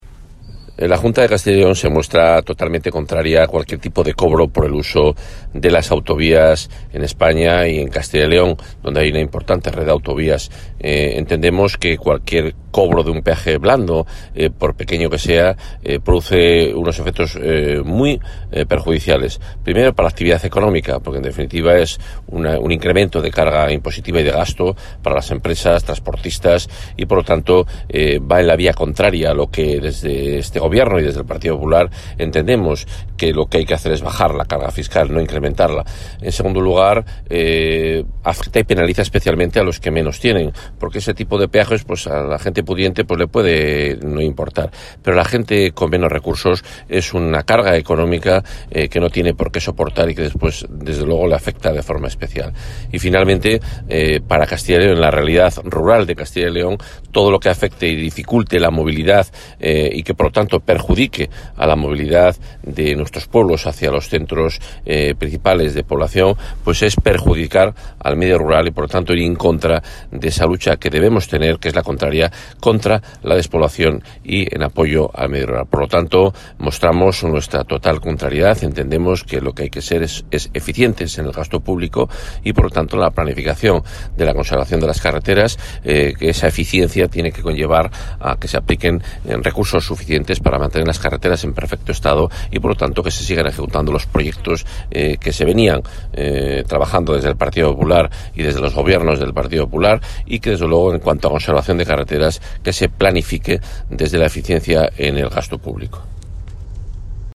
Según declaraciones del consejero de Fomento y Medio Ambiente, Juan Carlos Suárez-Quiñones, cualquier cobro de un peaje blando,...
Declaraciones del consejero.